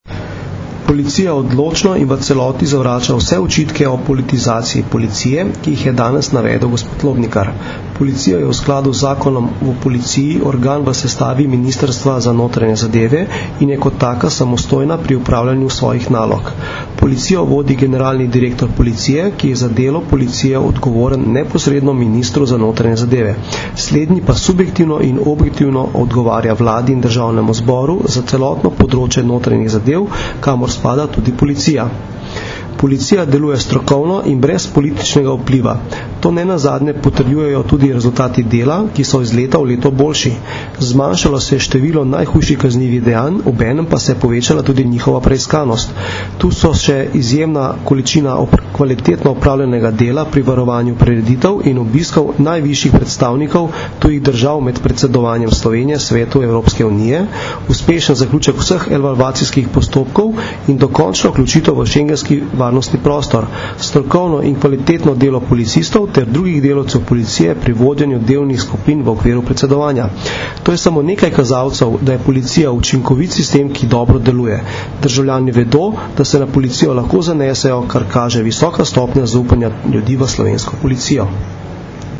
Zvočni posnetek izjave (mp3)